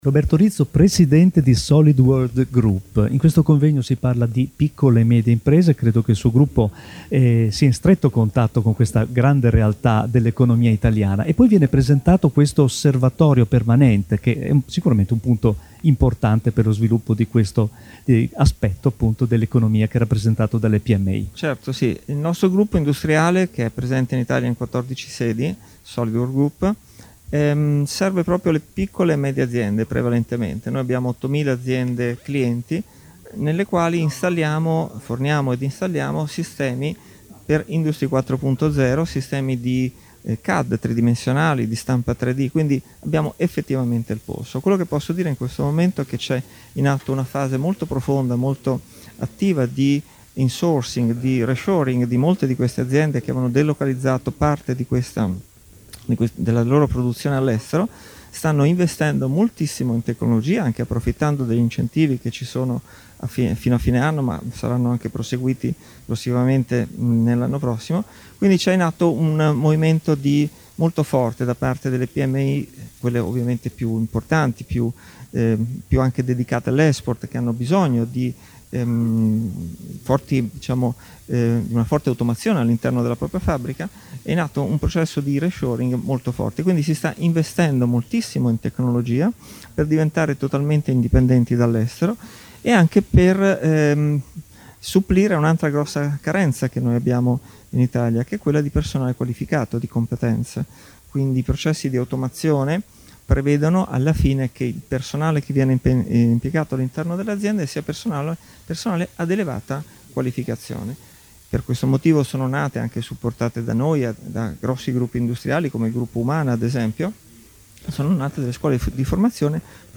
Lieti di essere main sponsor di questo importante evento, “Strategia per il rinascimento delle PMI”, organizzato AICIM e AISOM presso il Grand Hotel Magestic già Baglioni di Bologna.
Intervista a